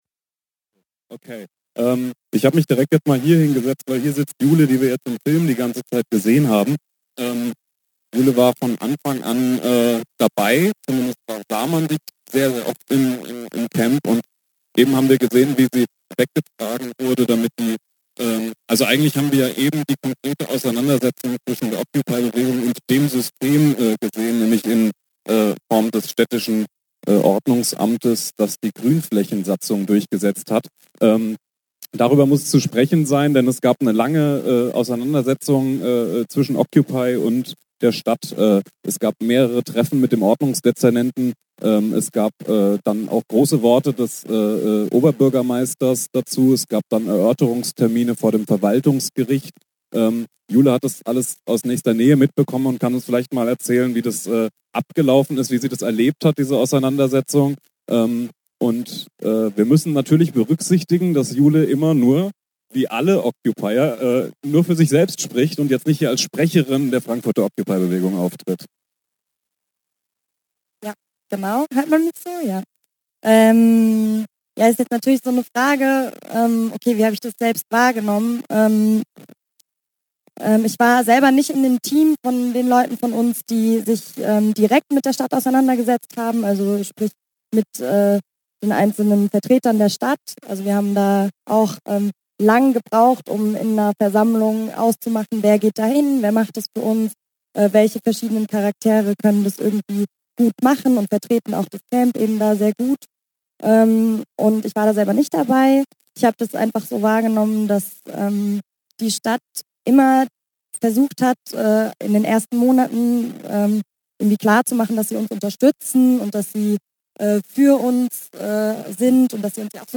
Radio99Prozent bei iTunes - Radio99Prozent bei Bitlove - RSS-Feed Text - RSS-Feed Mediendateien - Radio99Prozent auf UKW Blockupy 121020 Podium Teil 1 Diskussionsveranstaltung in Frankfurt.